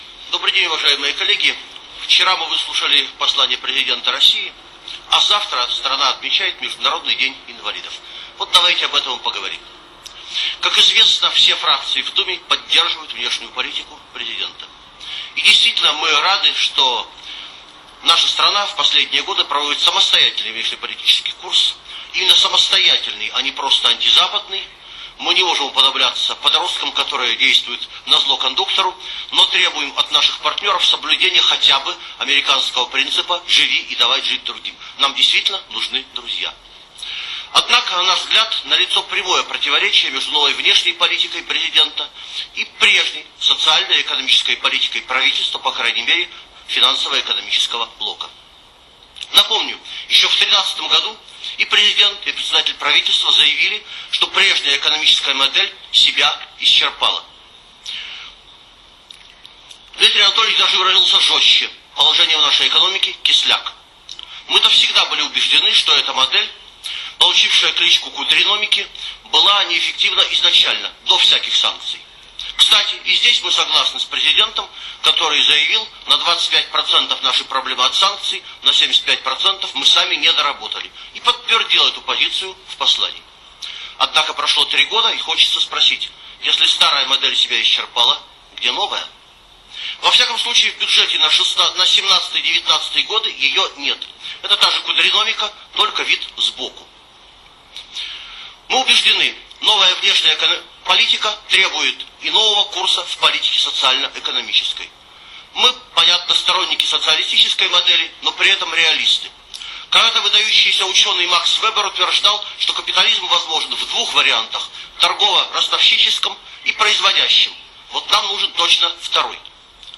Выступление Олега Смолина 2 декабря на пленарном заседании Госдумы.